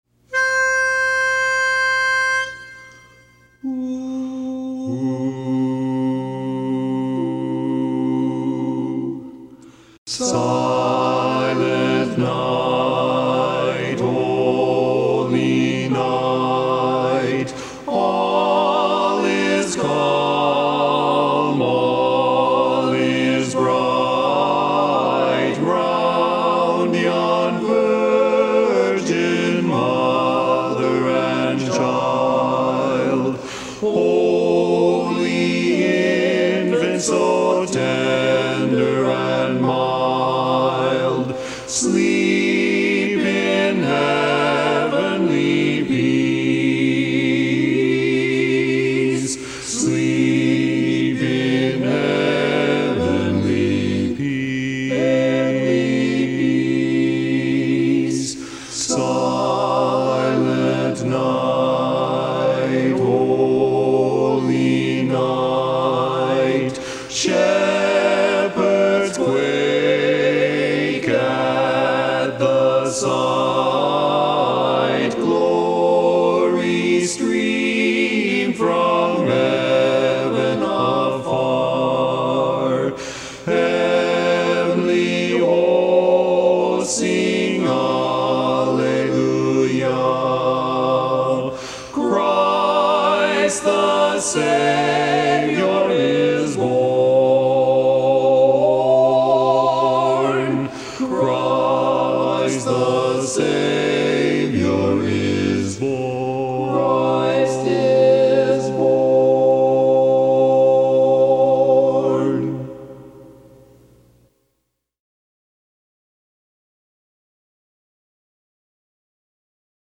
Barbershop
Bass